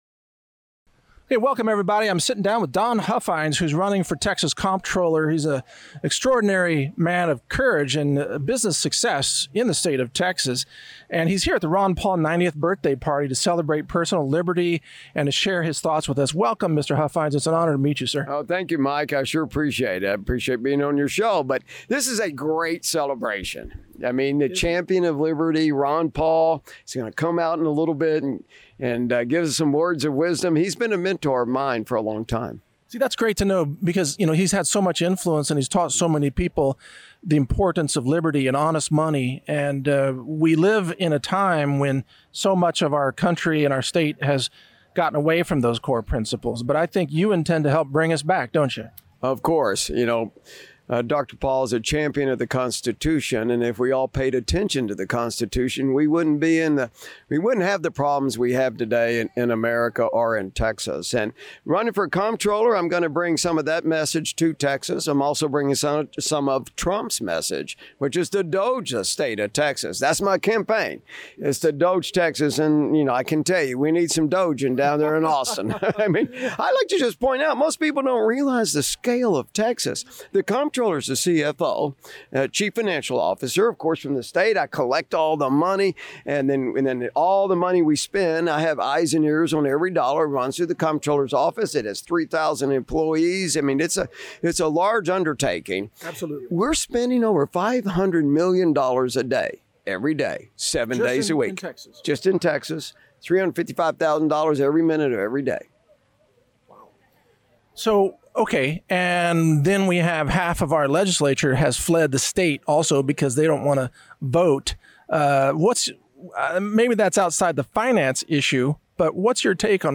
Mike Adams Interviews Don Huffines — Texas Comptroller Race, Sound Money, and Tax Reform - Natural News Radio